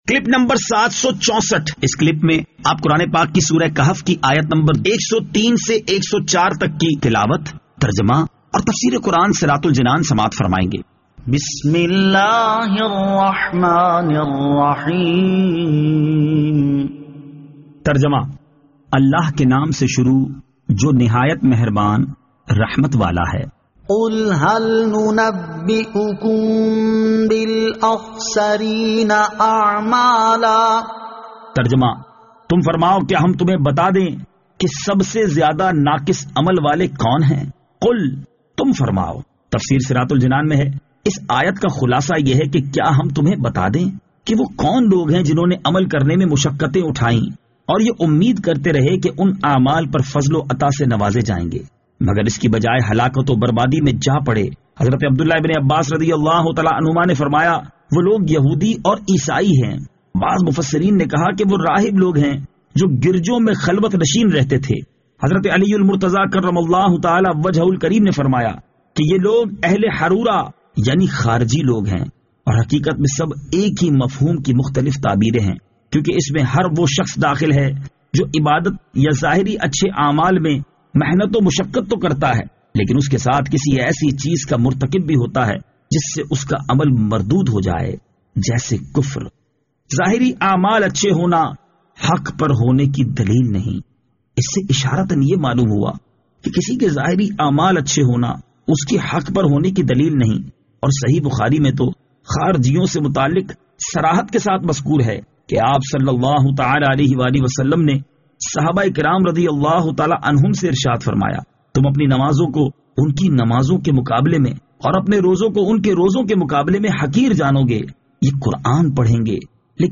Surah Al-Kahf Ayat 103 To 104 Tilawat , Tarjama , Tafseer